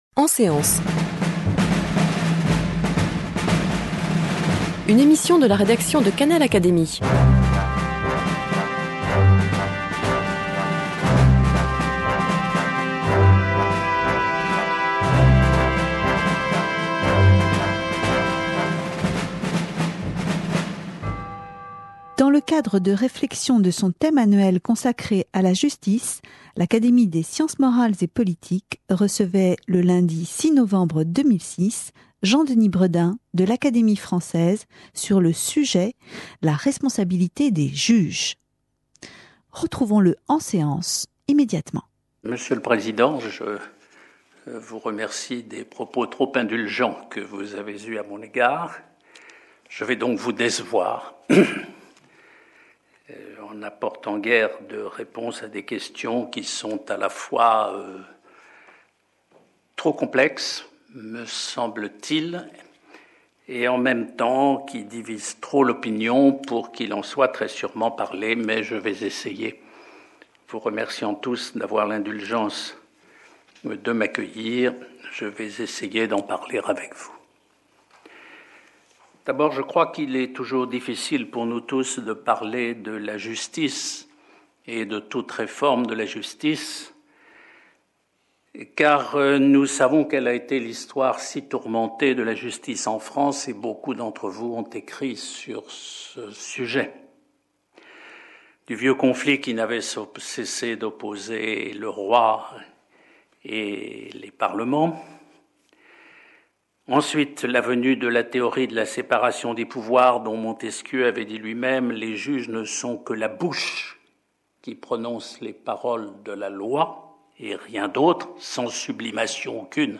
Communication de Jean-Denis Bredin de l’Académie française, prononcée en séance devant l’Académie des sciences morales et politiques, le lundi 6 novembre 2006.